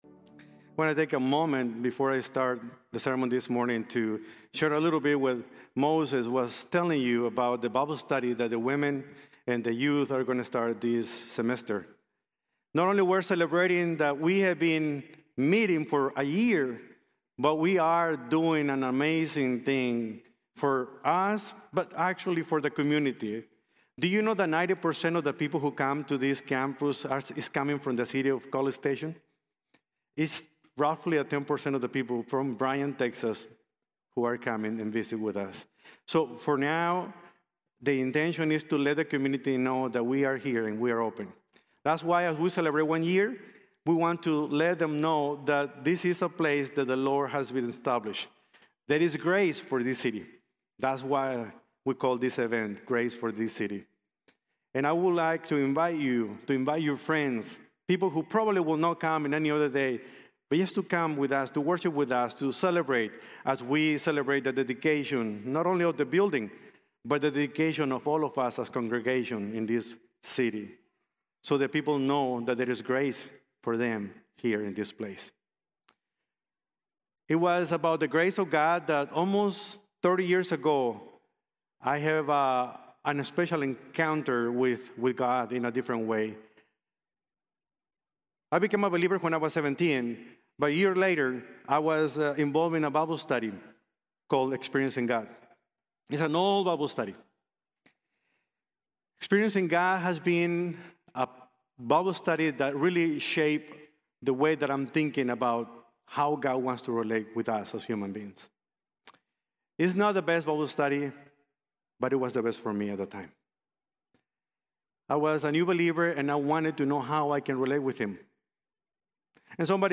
ESCHATOLOGY: The Doctrine of the Future | Sermon | Grace Bible Church